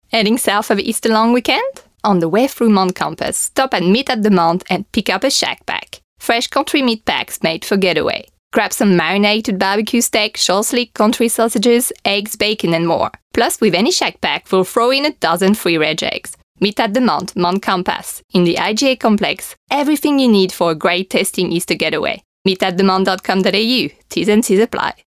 French Voiceover Download Audio